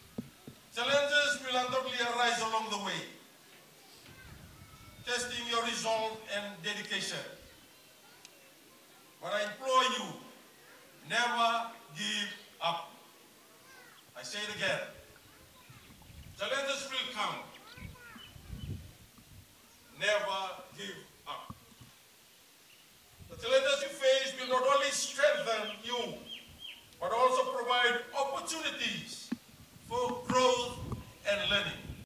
Speaking to the graduates Attorney General Siromi Turaga reminded them to use their challenges as a stepping stone to enhance their capabilities.